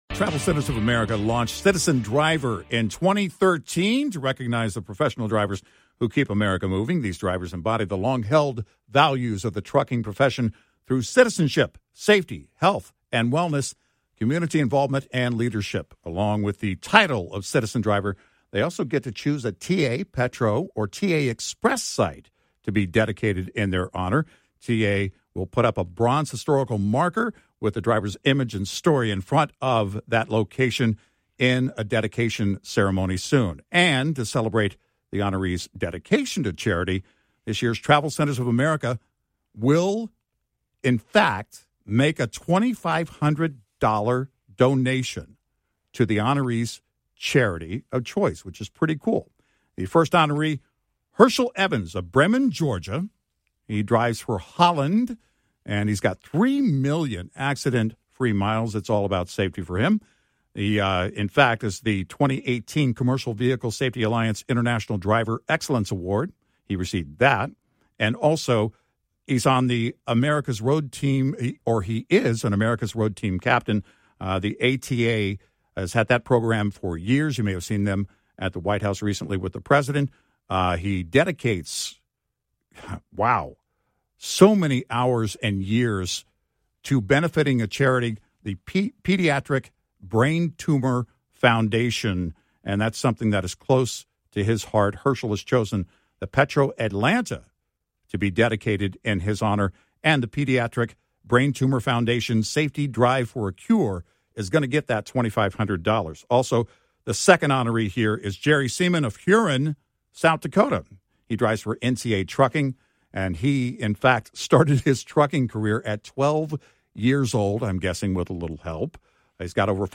Announce TravelCenters of America’s seventh annual Citizen Driver Award winners on air from June 10th.